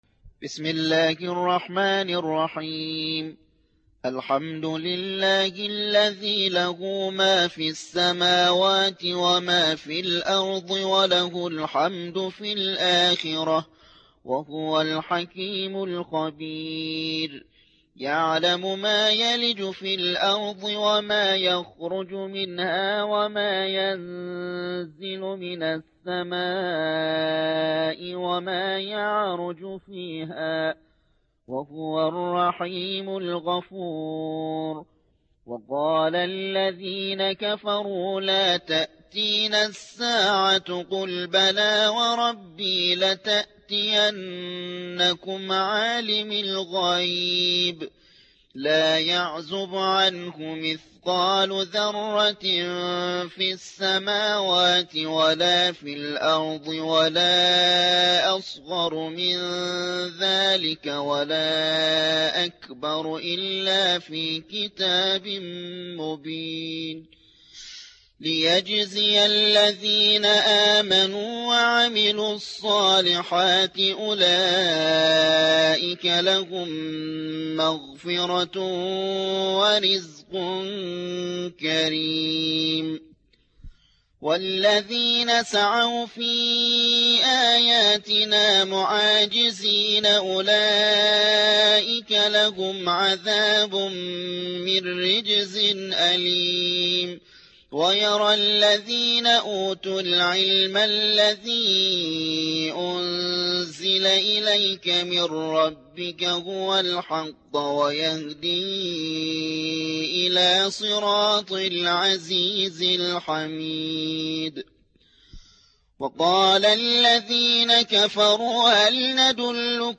القرآن الكريم